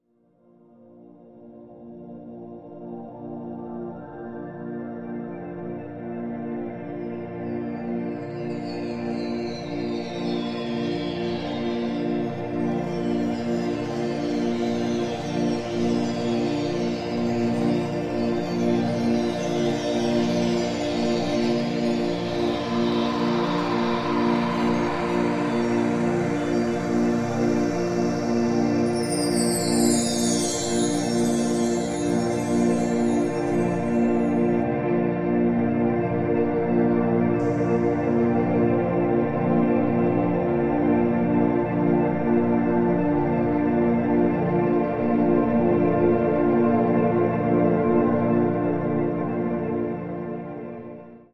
Rebalancing and uplifting.